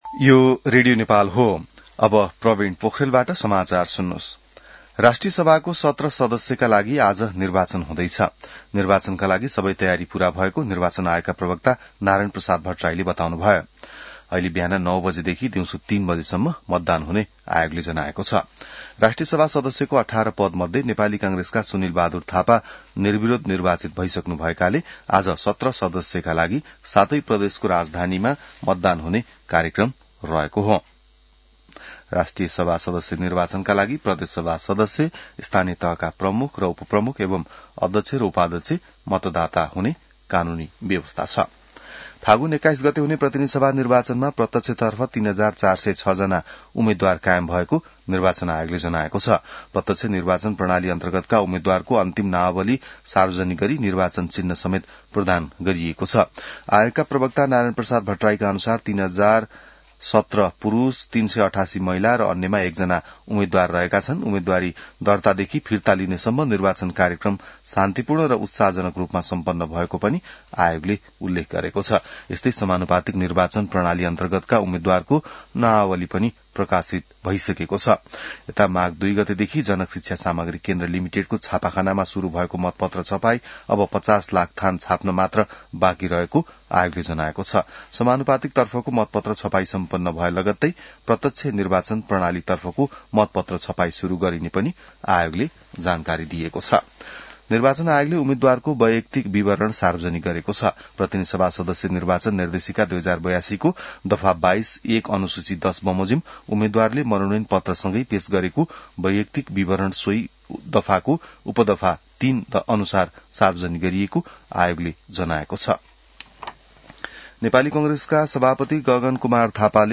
बिहान ६ बजेको नेपाली समाचार : ११ माघ , २०८२